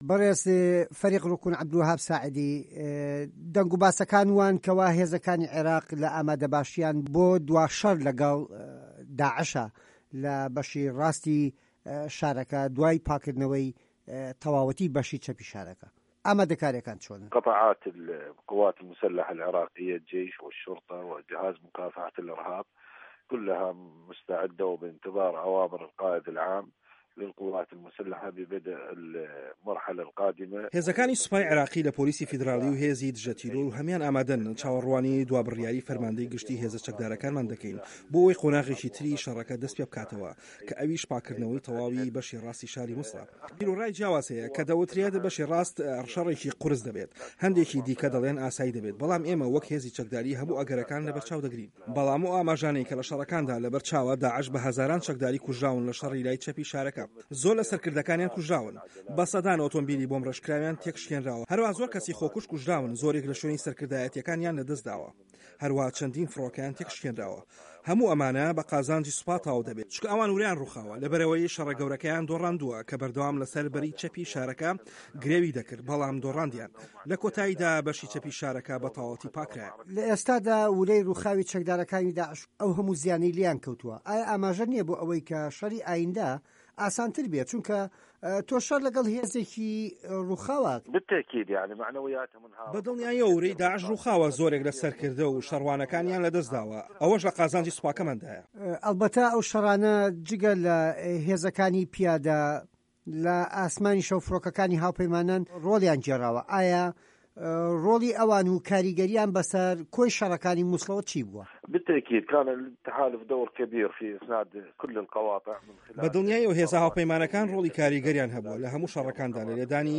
وتووێژ لەگەڵ فه‌ریق روکن عه‌بدولوه‌هاب ئه‌لساعدی